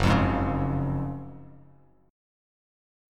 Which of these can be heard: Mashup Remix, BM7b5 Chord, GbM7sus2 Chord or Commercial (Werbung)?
GbM7sus2 Chord